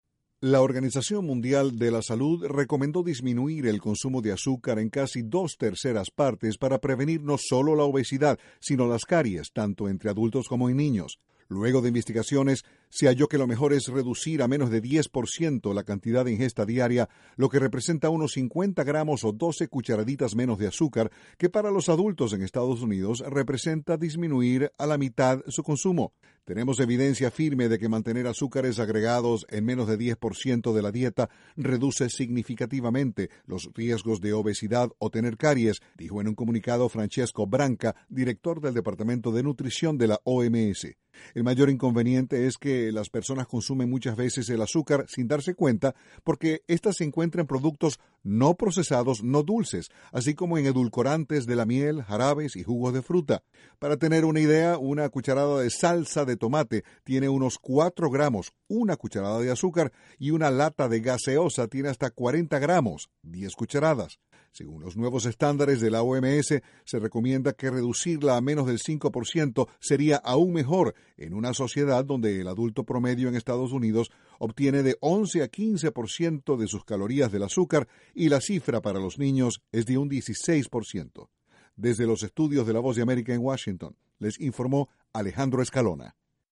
Según la Organización Mundial de la Salud, adultos y niños consumen demasiada azúcar, a veces sin saberlo. Desde los estudios de la Voz de América, en Washington